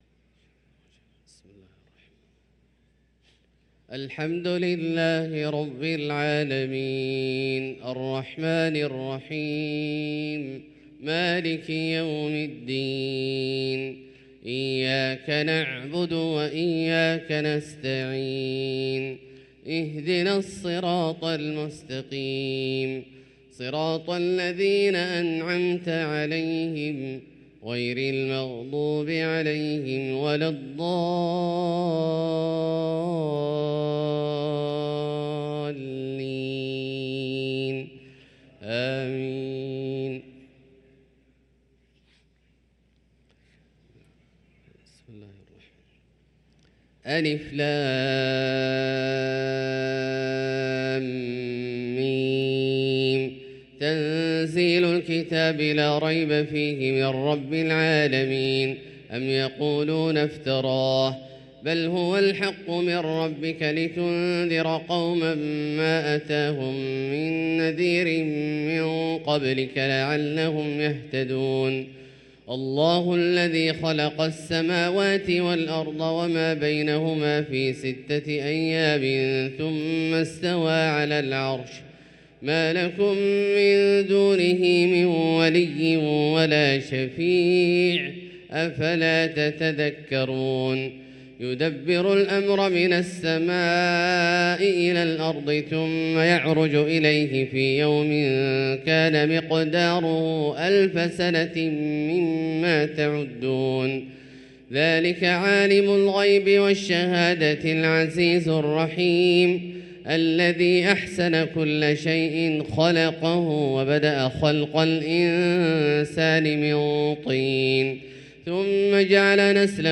صلاة الفجر للقارئ عبدالله الجهني 14 ربيع الأول 1445 هـ
تِلَاوَات الْحَرَمَيْن .